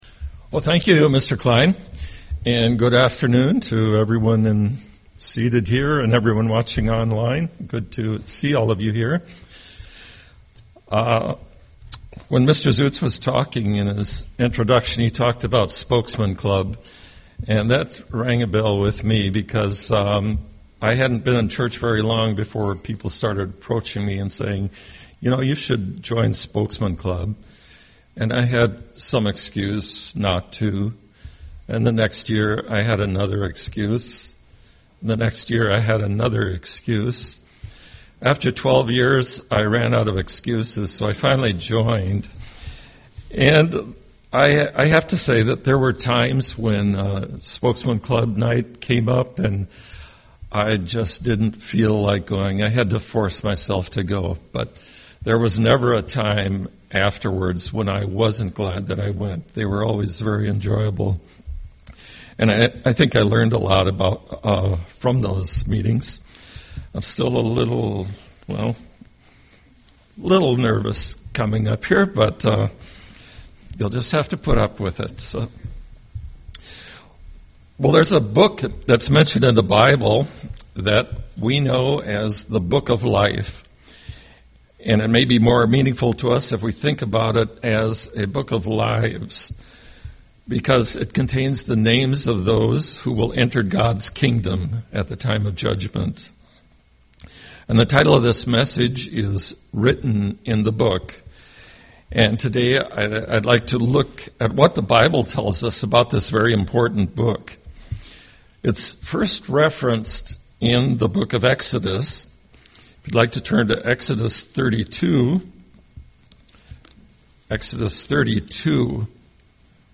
UCG Sermon book of life Studying the bible?